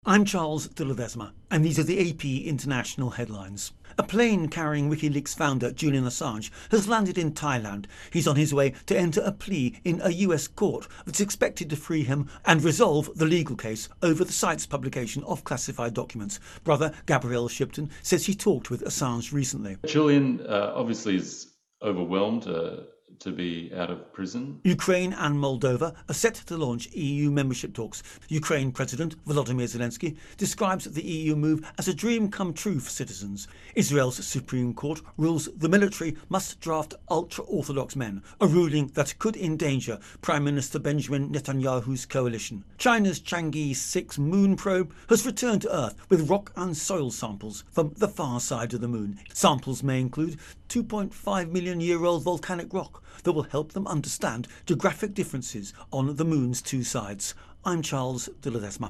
The latest international headlines